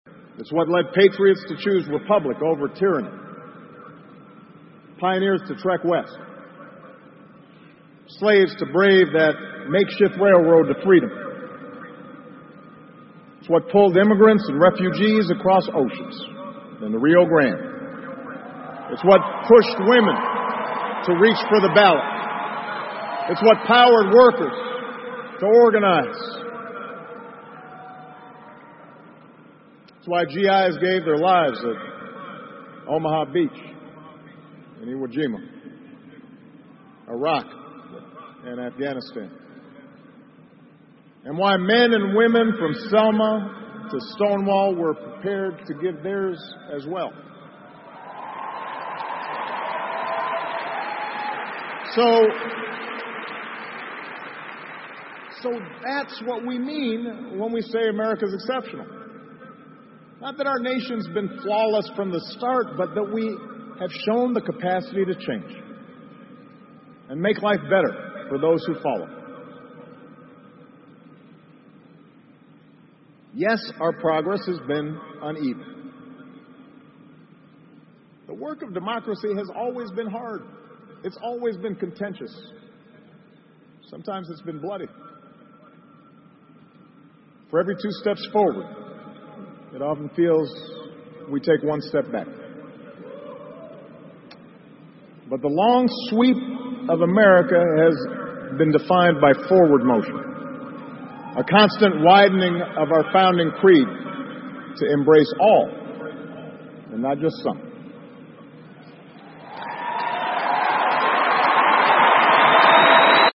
奥巴马每周电视讲话：美国总统奥巴马告别演讲(3) 听力文件下载—在线英语听力室